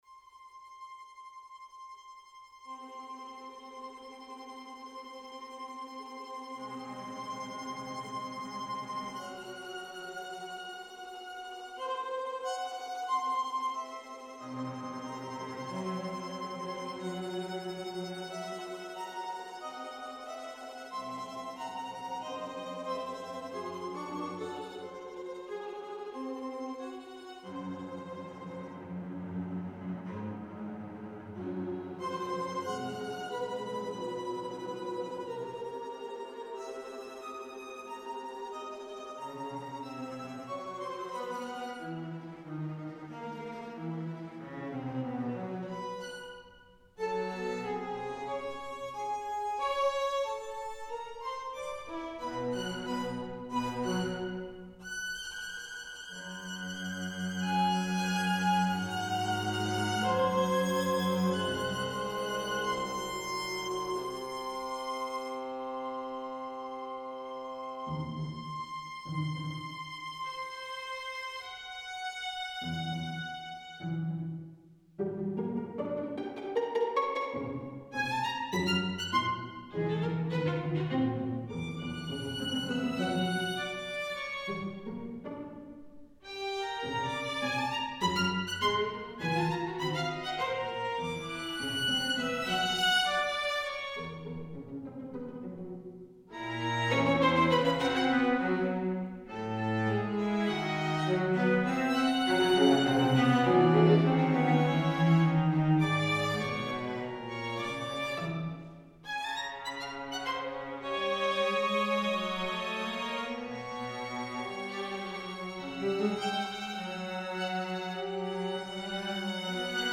For string quartet.